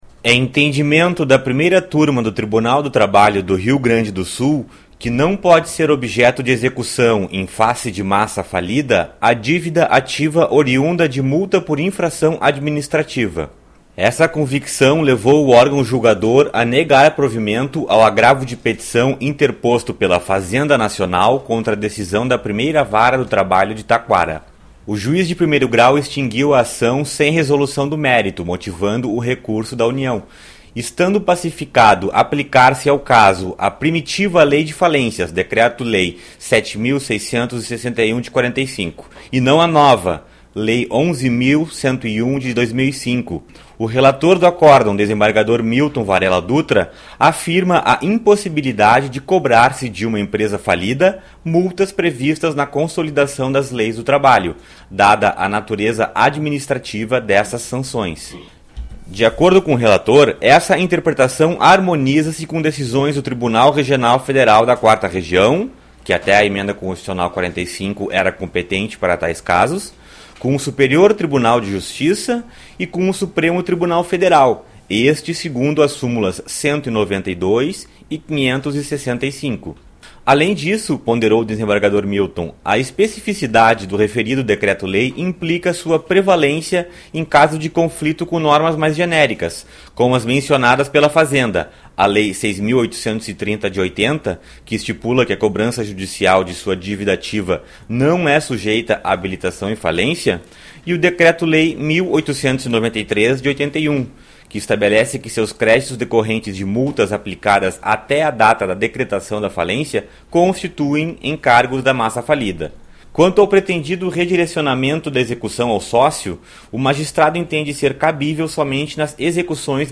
Áudio da notícia - 01